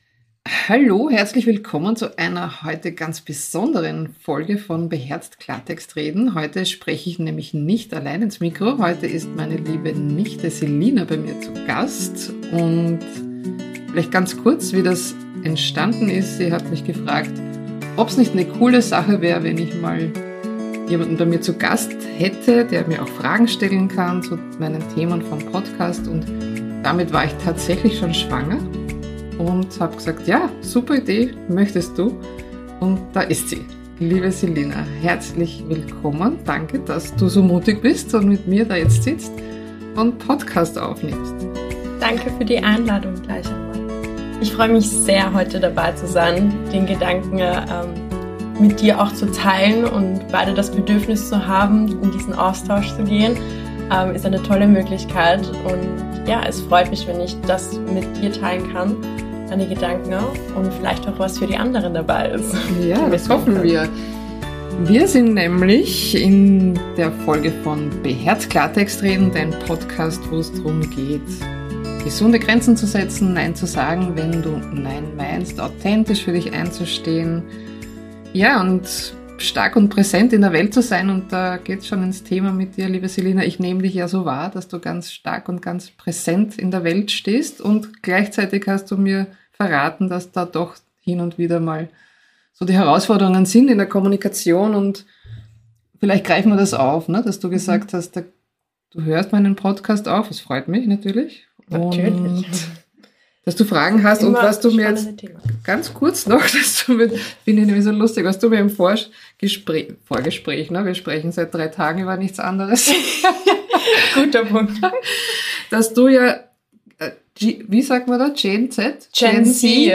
#30 | Zwischen Zugehörigkeit, People Pleasing und Selbstfürsorge - ein berührender Dialog